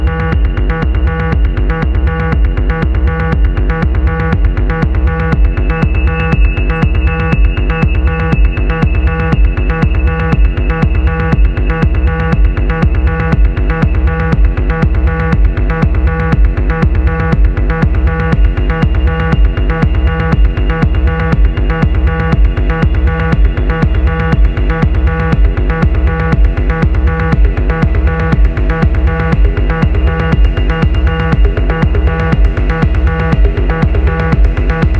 Жанр: Танцевальные / Техно